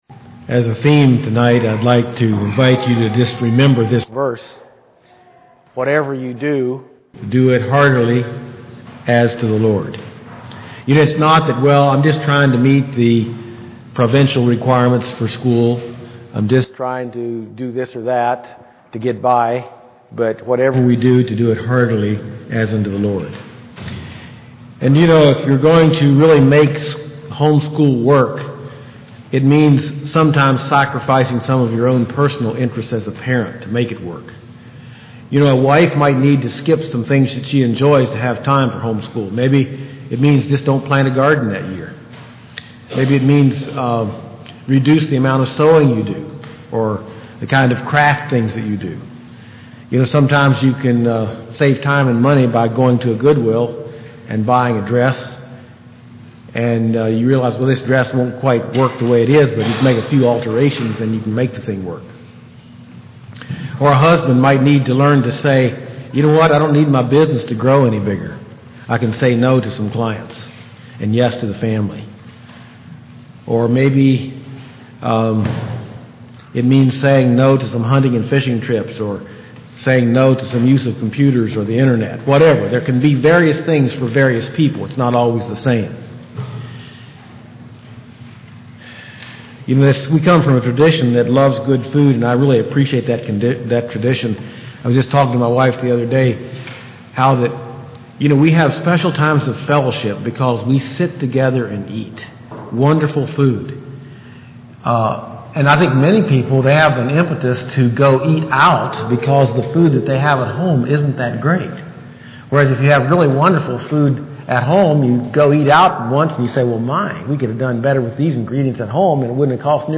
Series: Home School Conference Service Type: Saturday Evening %todo_render% « Developing In Your Child a Heart for Missions God